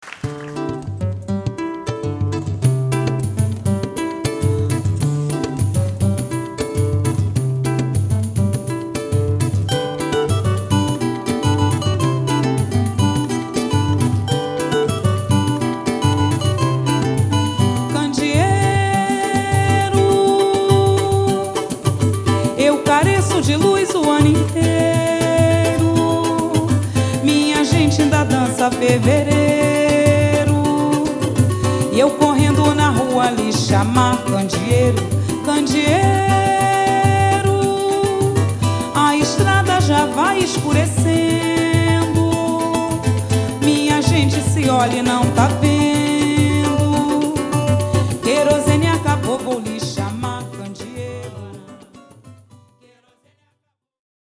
2005年春、ブラジル／リオ・デ・ジャネイロ録音の通算3作目となるライブ・レコーディング作品。